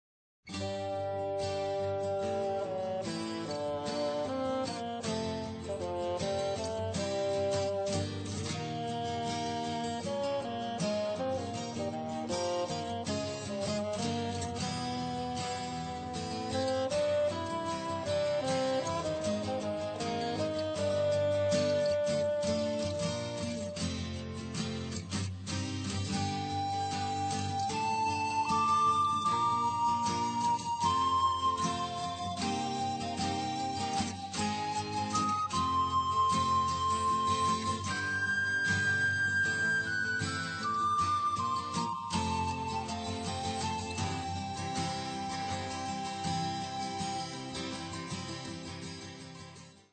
Stereo, 0:49, 64 Khz, (file size: 391 Kb).